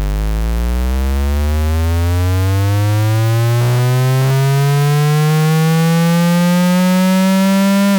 03 D'ou reparte 2 autres fils qio vont au MiniDisc, avec lequel on réalise un enregistrement.
On s'assure que la route est dégagée, on lance l'enregistrement, on met la seconde, et on accélère a fond, si possible jusqu'au rupteur, puis on met la troisième, on décélère, et on arrête l'enregistrement.
acceleration.wav